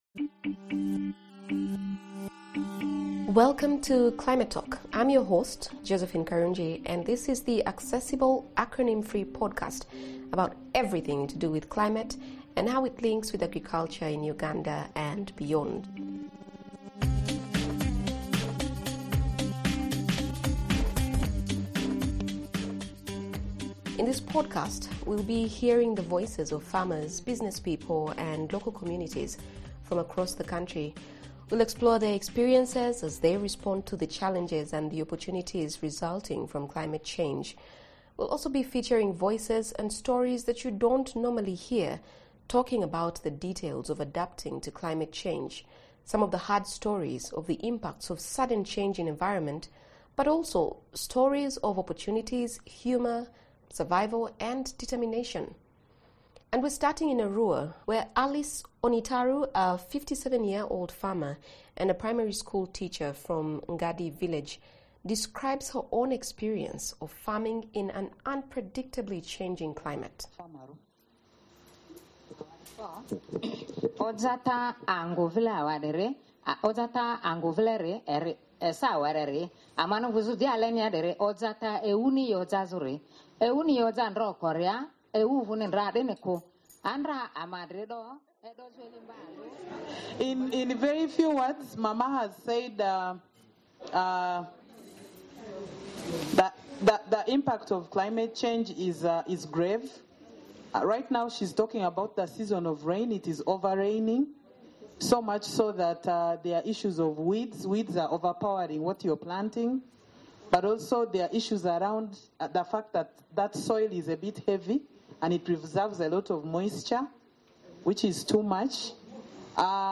ABOUT THIS EPISODE This is an all-new podcast exploring the many ways that rural communities and individuals in Uganda are responding to climate change. In this first episode, we hear from smallholder farmers in Arua, Gulu, and Lira about how climate change is affecting their lives on a day-to-day basis.